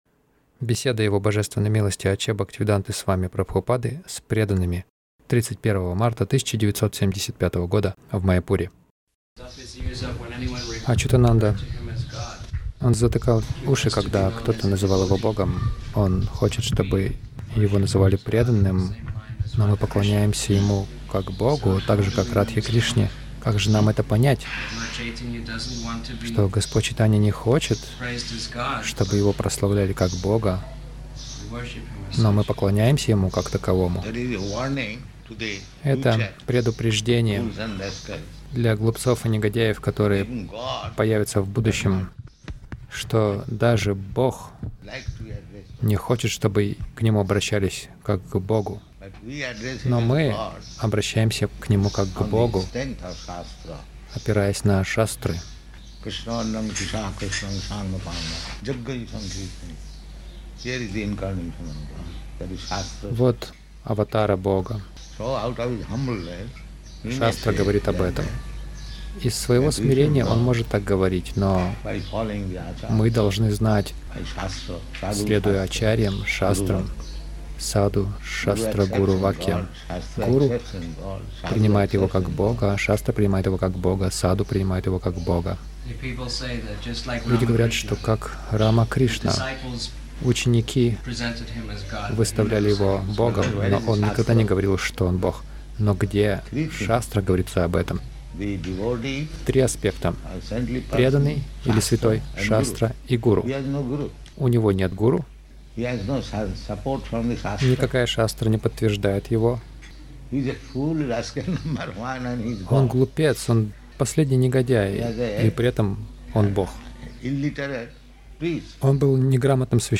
Беседа — Мы должны следовать нашим ачарьям
Милость Прабхупады Аудиолекции и книги 31.03.1975 Беседы | Маяпур Беседа — Мы должны следовать нашим ачарьям Загрузка...